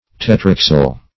Search Result for " tetraxile" : The Collaborative International Dictionary of English v.0.48: Tetraxile \Te*trax"ile\, a. [Tetra- + axile.]